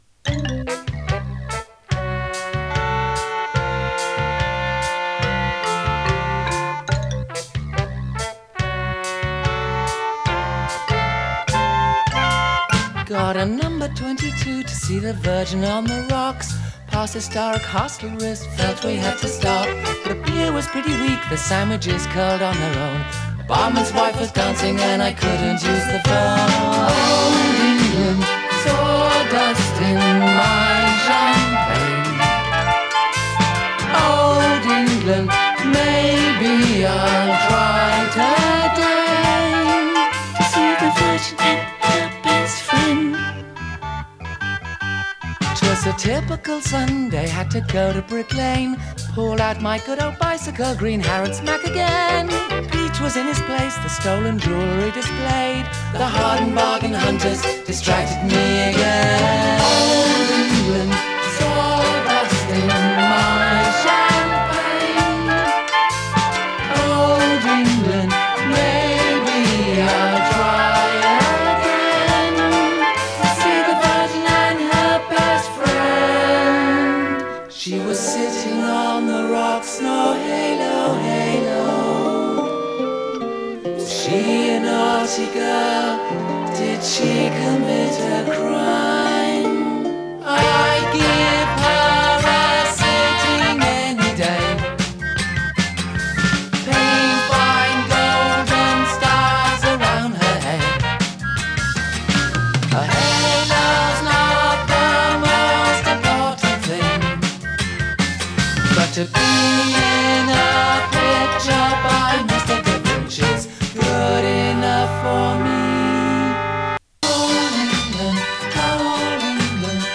ネオアコ
インディーポップ